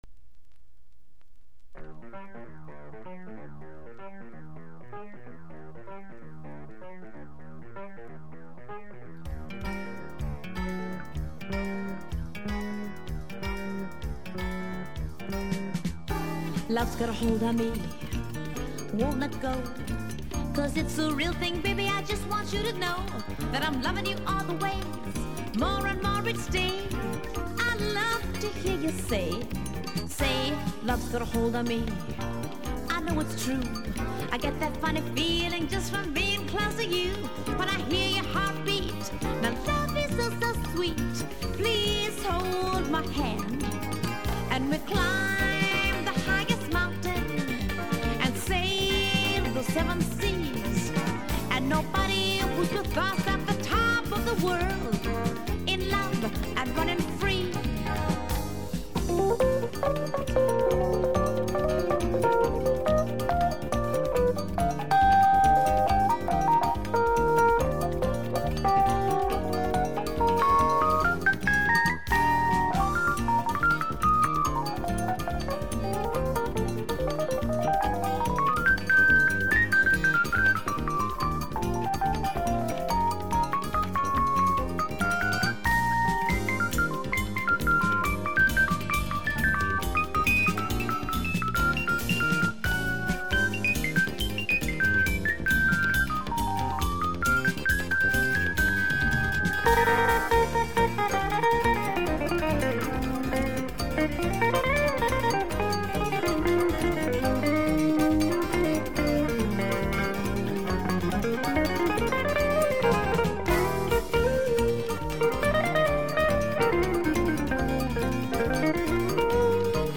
（プレスによりチリ、プチ音ある曲あり）※…